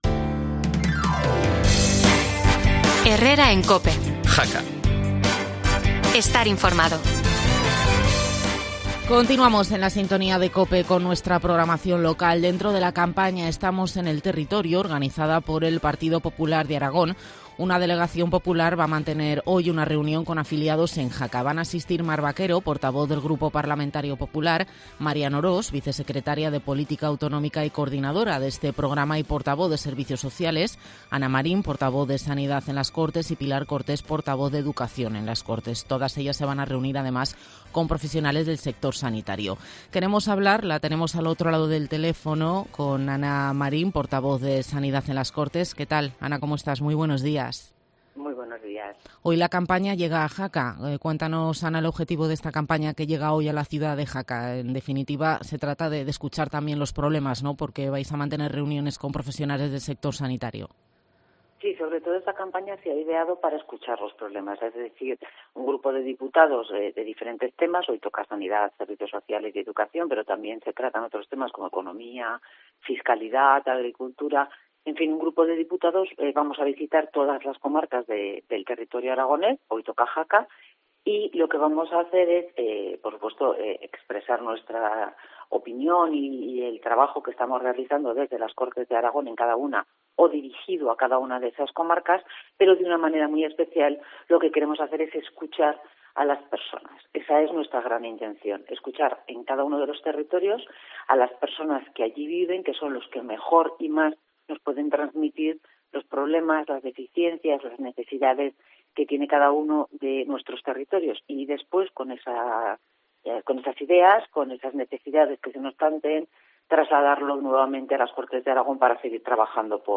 Audio Ana Marín, portavoz de Sanidad en las Cortes por el PP